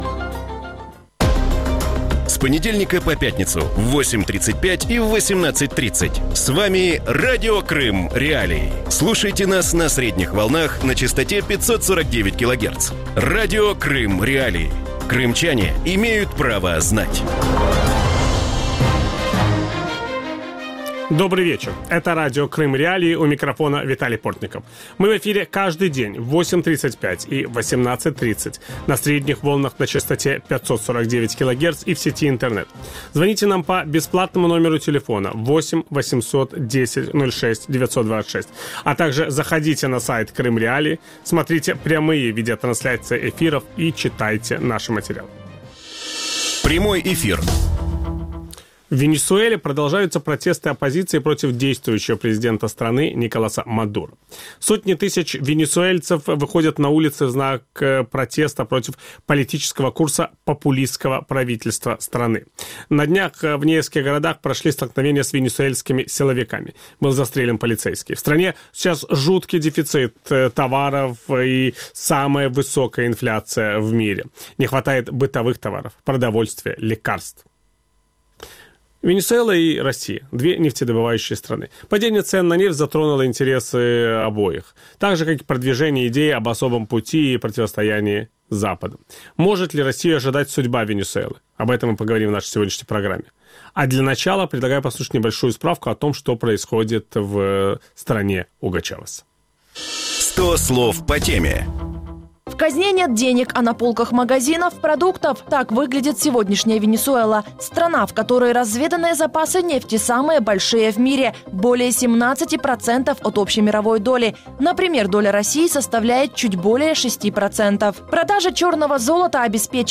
У вечірньому ефірі Радіо Крим.Реалії проводять паралелі між ситуацією в Росії і Венесуелі. Чому країна в Латинській Америці скотилася в глибоку політичну та економічну кризу, чи є шанс у нафтовидобувних країн стабілізувати економіку в епоху низьких цін на нафту і чи чекає Росію доля Венесуели?
Ведучий: Віталій Портников.